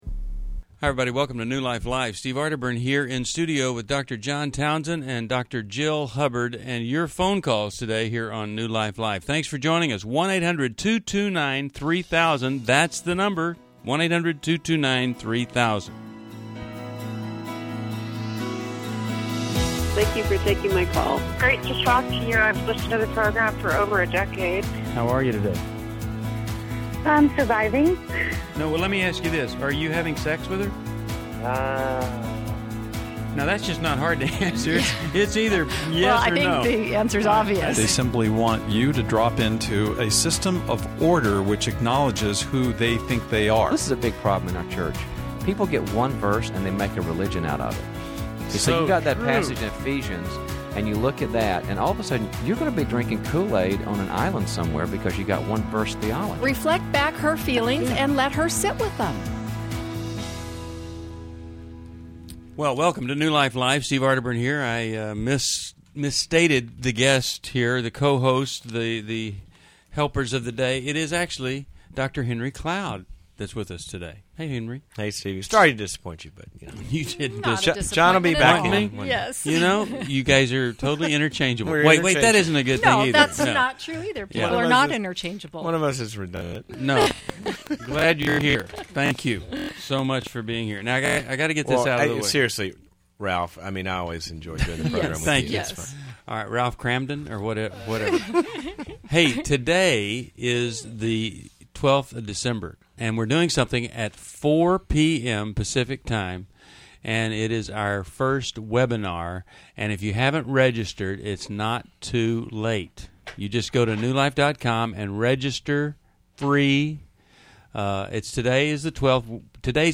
Caller Questions: How can I be supportive to my 23yo single and pregnant sister?